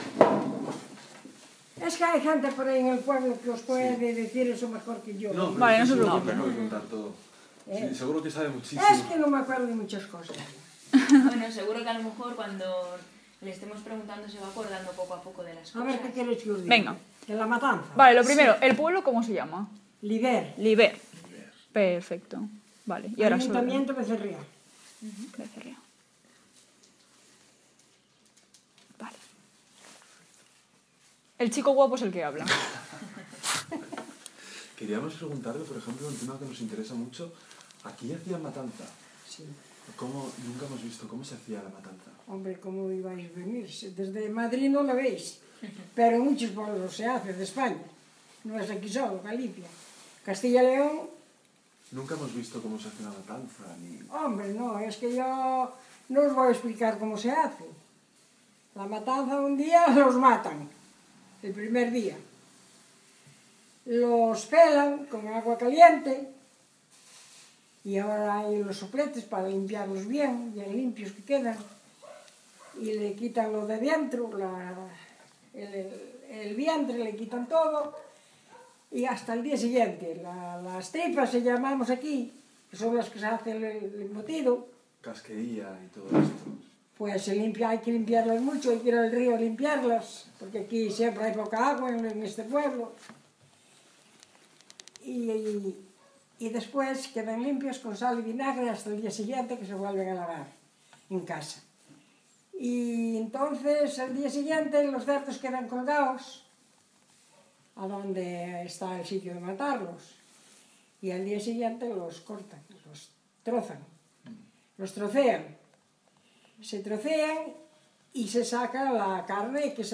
Locality Liber (Becerre�)
mujer